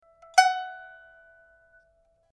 pipa20.mp3